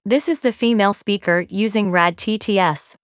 Supported voice names and samples generated with these models are also mentioned in the following table.
Female1_RadTTS.wav